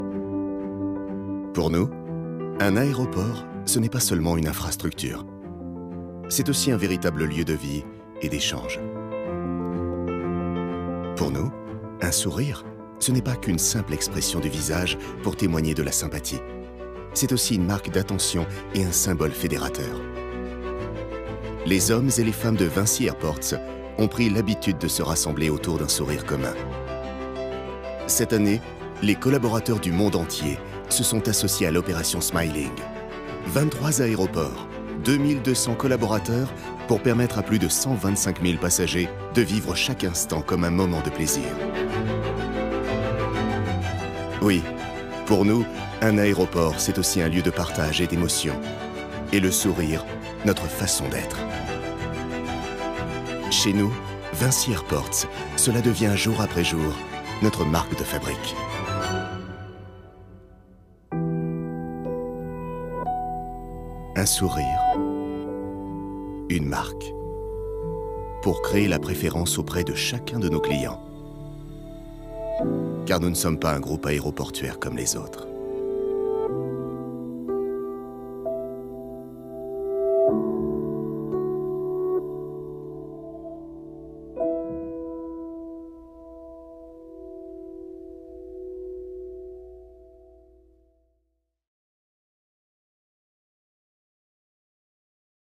Prestation voix-off pour Vinci Airports - "Smiling" : élégant, chaleureux et authentique
Doux, naturel et chaleureux.
Enregistré chez Yellow Cab.